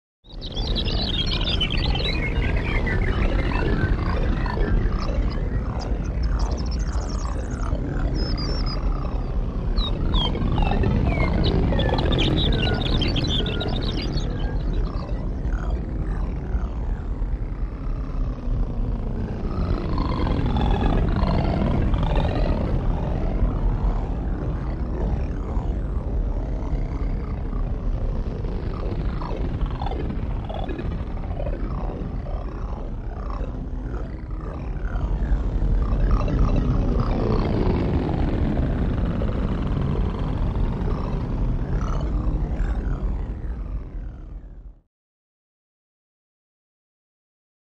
Synthesized Background | Sneak On The Lot
Synthesized Jungle Background; High Frequency Bird Beeps And Low Frequency Robot Frogs With Oscillating Bubble Type Effect.